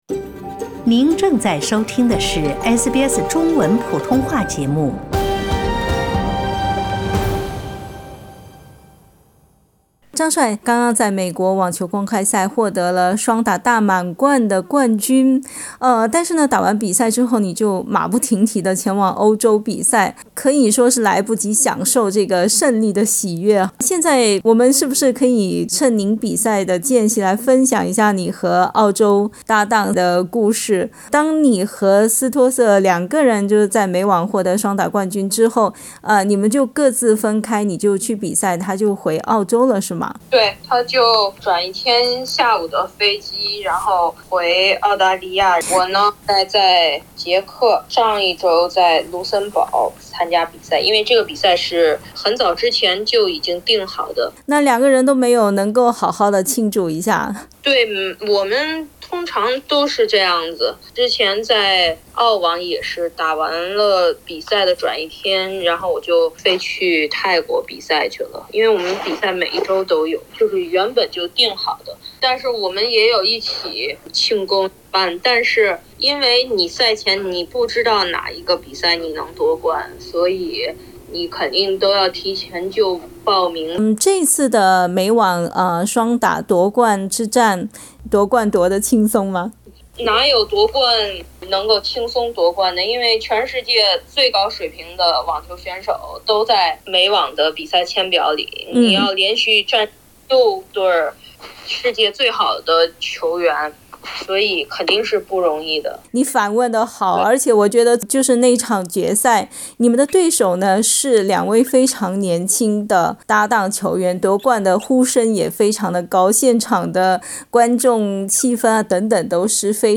在緊張的比賽間隙，張帥在捷克的一個酒店房間裡接受了SBS普通話的電話寀訪。
雖然比賽日期排得很滿，訓練也很緊張，但是張帥總是保持從容的狀態，在訪談中談笑風生。